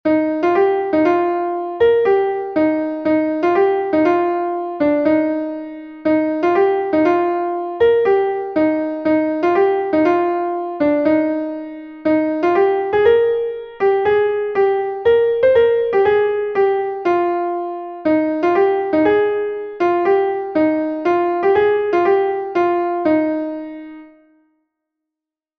Ton Bale Er Gemene is a Bale from Brittany